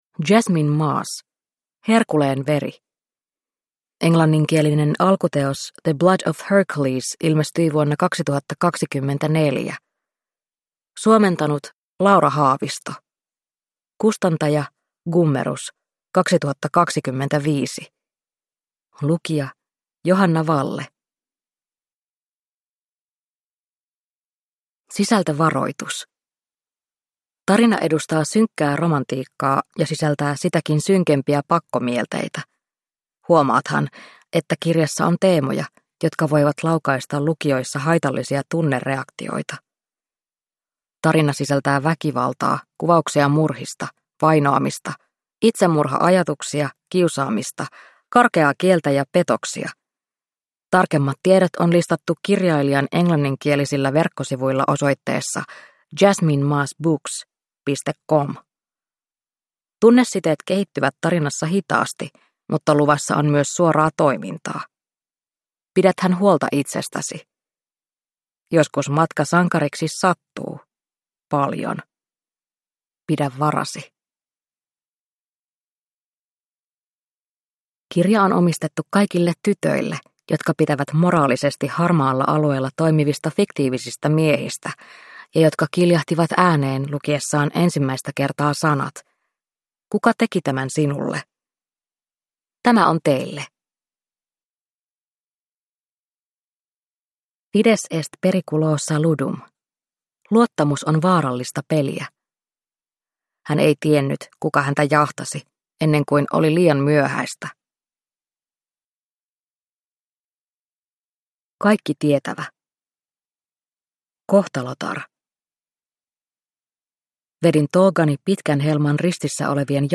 Herkuleen veri (ljudbok) av Jasmine Mas